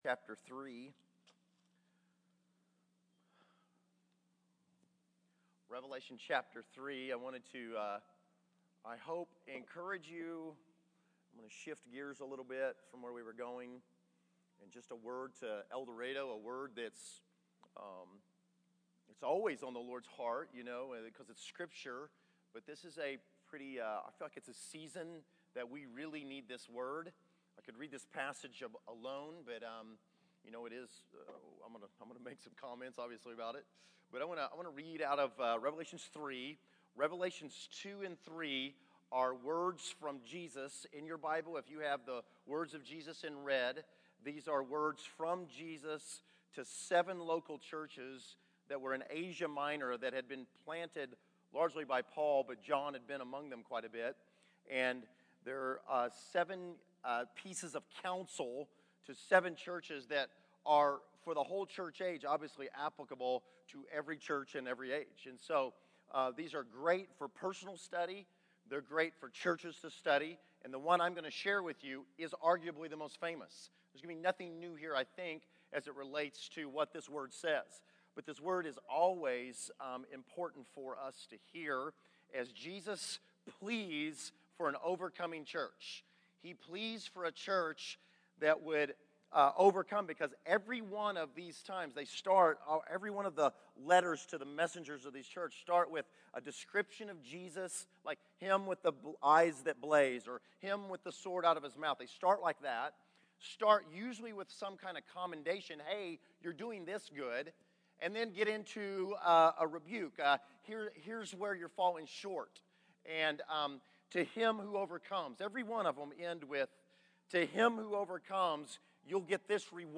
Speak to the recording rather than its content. Category: Sermons | Location: El Dorado